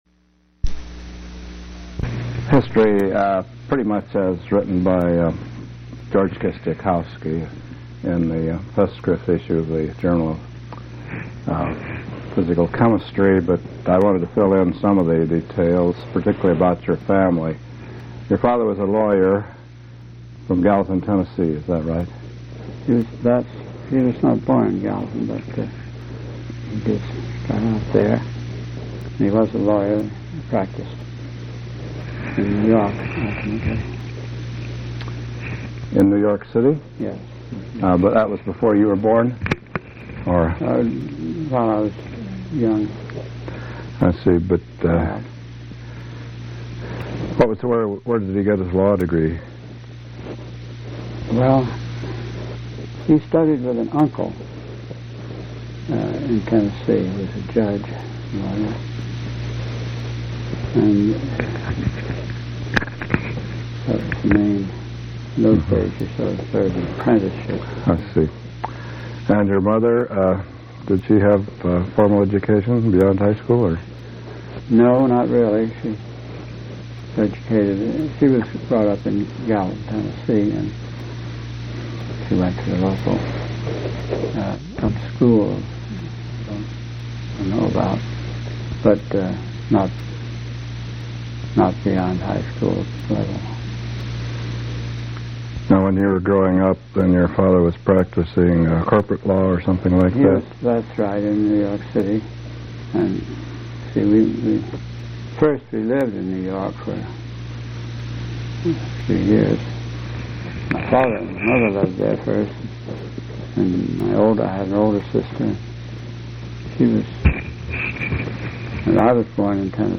Oral history interview with E. Bright Wilson, Jr.